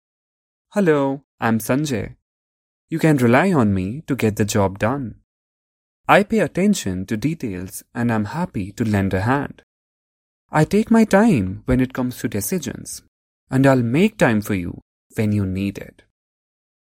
Male
Yng Adult (18-29), Adult (30-50)
Natural Speak
Disc Personality Demo
Words that describe my voice are Believable, Conversational, Sincere, Articulate.